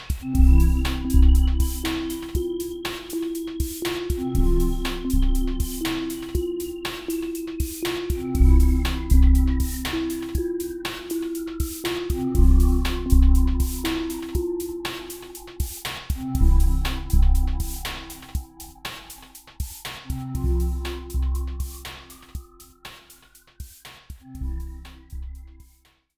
Audiorecording, Musik & Sounddesign